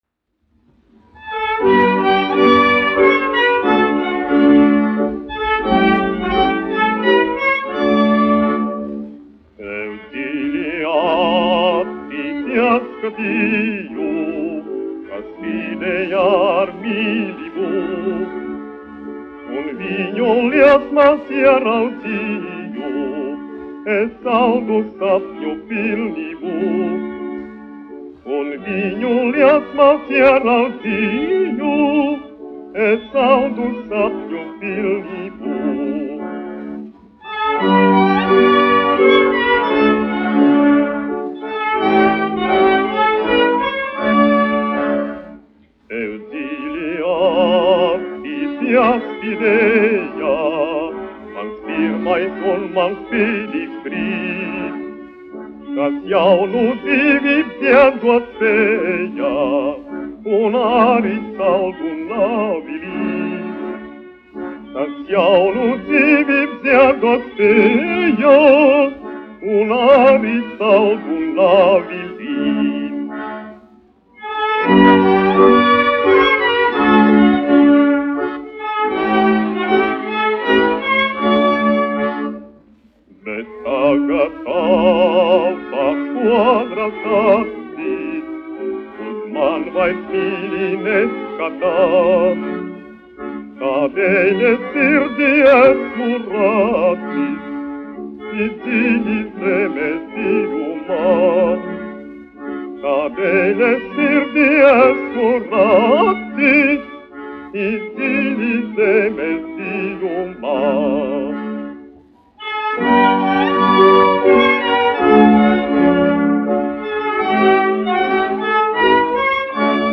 1 skpl. : analogs, 78 apgr/min, mono ; 25 cm
Populārā mūzika -- Latvija
Skaņuplate
Latvijas vēsturiskie šellaka skaņuplašu ieraksti (Kolekcija)